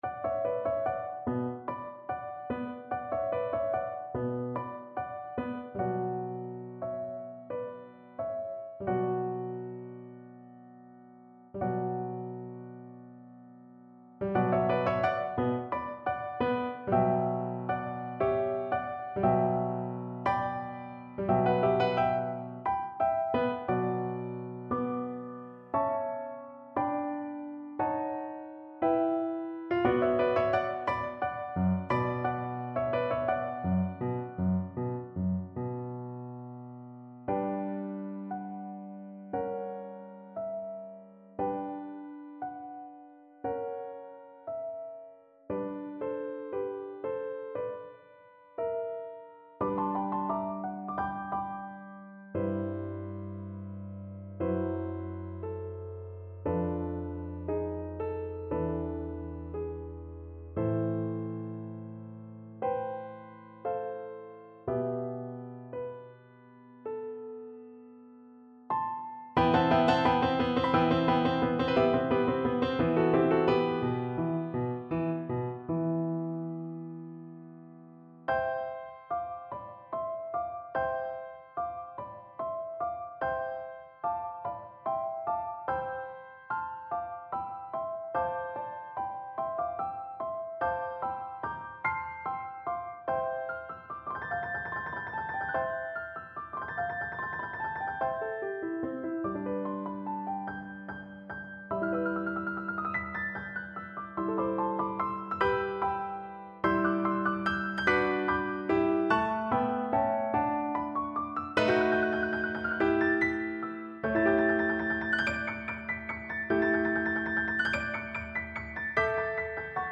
= 69 = 100 Allegro (View more music marked Allegro)
4/8 (View more 4/8 Music)
Classical (View more Classical Cello Music)